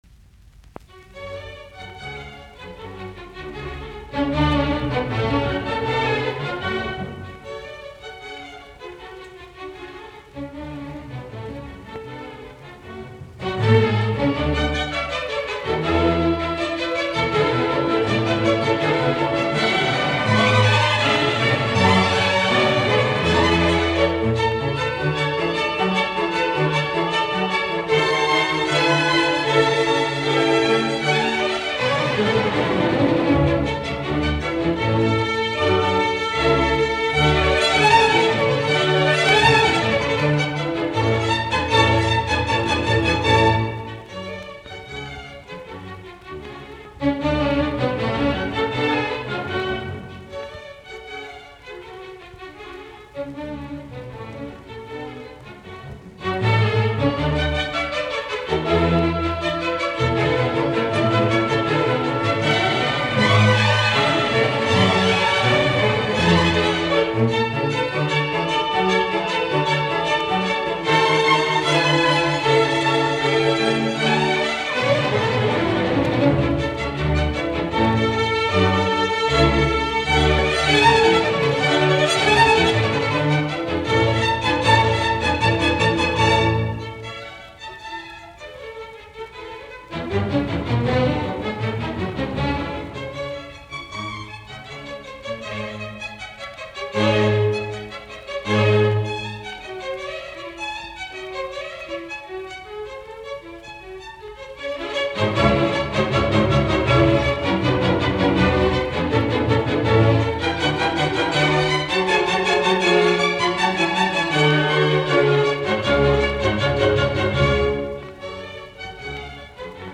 fis-molli
Presto - Adagio